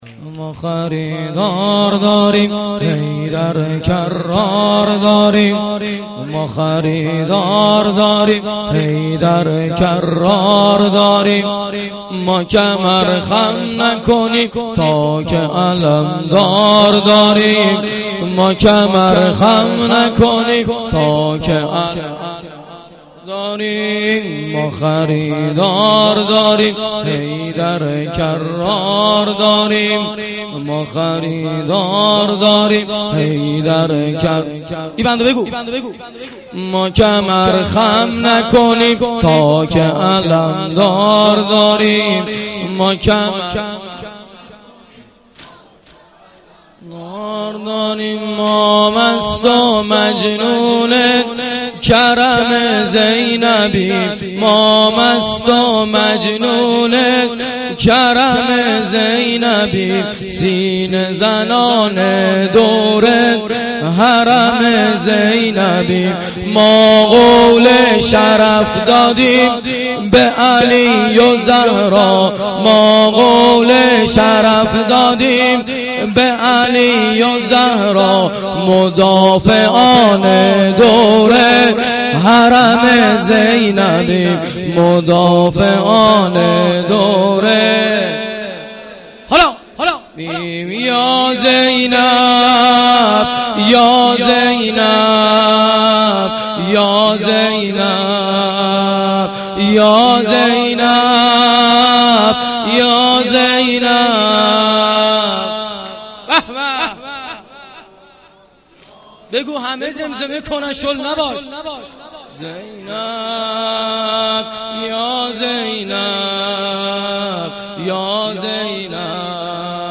گزارش صوتی جلسه هفتگی2دیماه